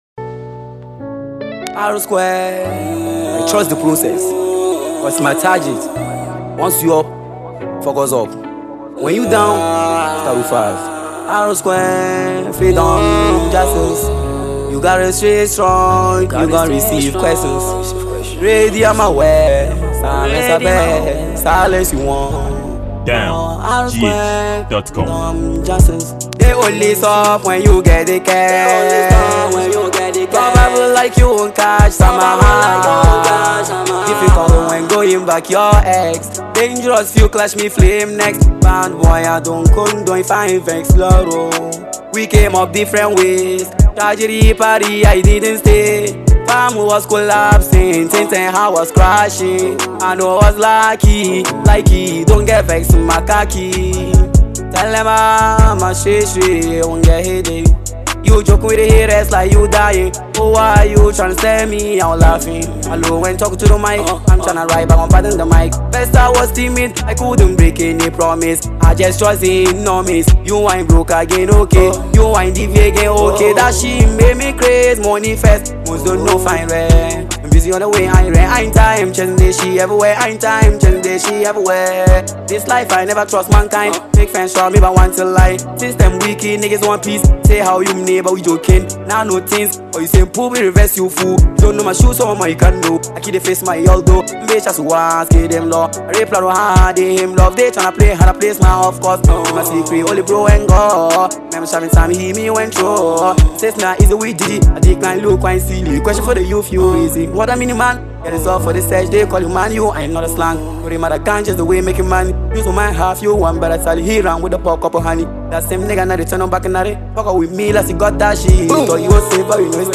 Ghana Music
Ghanaian fast rising music duo